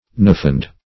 Search Result for " nefand" : The Collaborative International Dictionary of English v.0.48: Nefand \Ne"fand\, Nefandous \Ne*fan"dous\, a. [L. nefandus not to be spoken; ne not + fari to speak.]